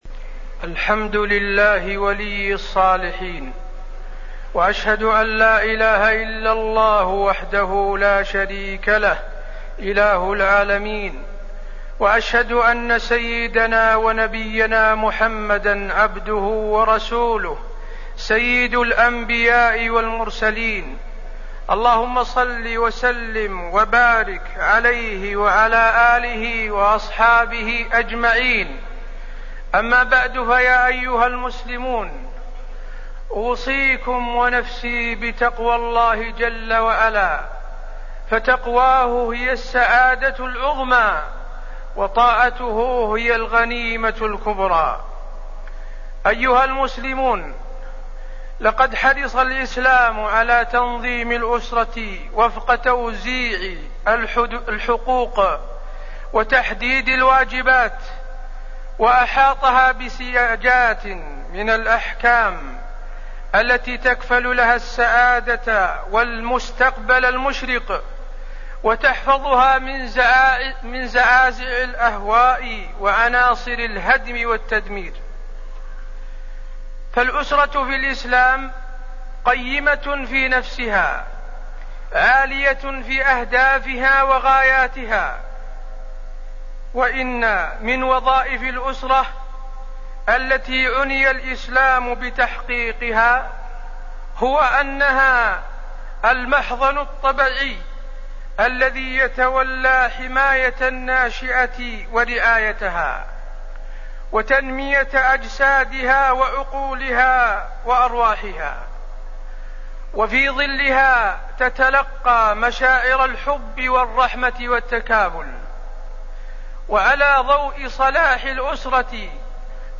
تاريخ النشر ١٨ صفر ١٤٣٠ هـ المكان: المسجد النبوي الشيخ: فضيلة الشيخ د. حسين بن عبدالعزيز آل الشيخ فضيلة الشيخ د. حسين بن عبدالعزيز آل الشيخ تنظيم الأسرة وتربية الأولاد